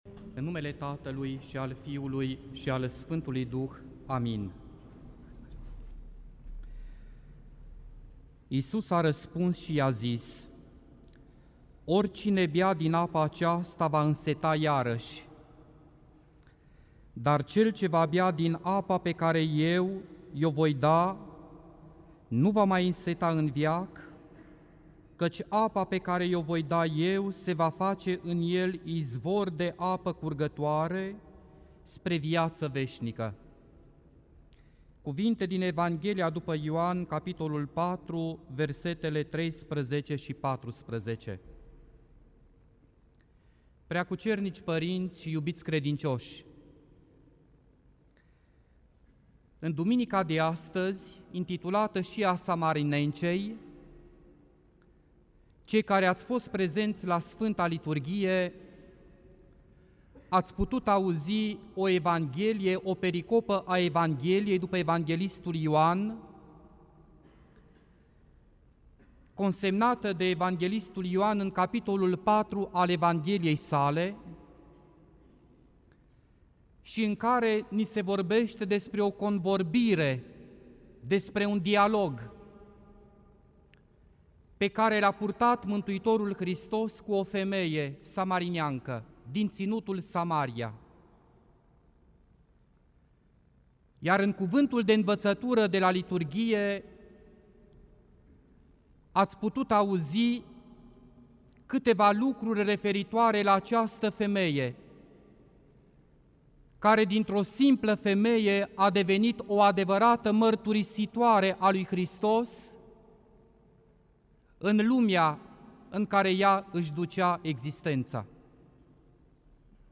Predică la Duminica a 5-a după Paști